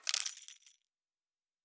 Objects Small 03.wav